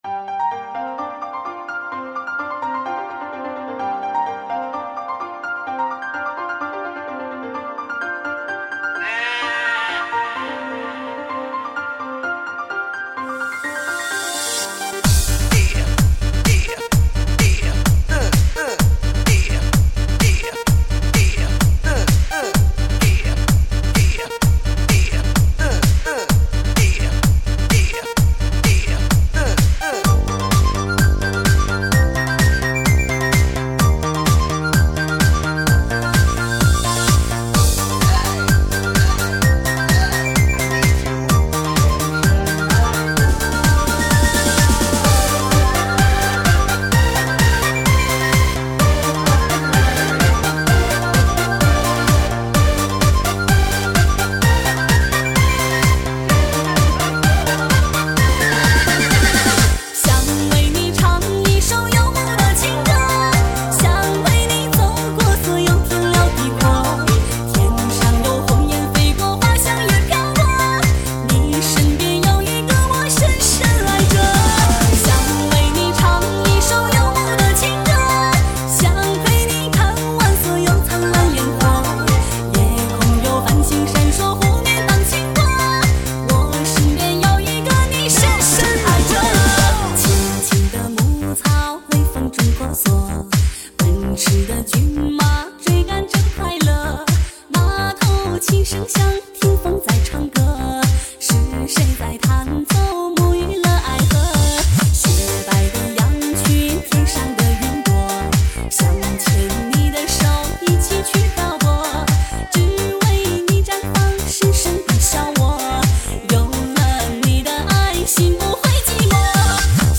最疯狂的舞曲 最震撼的音质 DISCO强悍出击 百分百热血沸腾
DJ慢摇掌门人极力推荐，深层节奏
新理念!全新HOUSE曲风，炫目而迷离
音乐狂飚，激情无以伦比！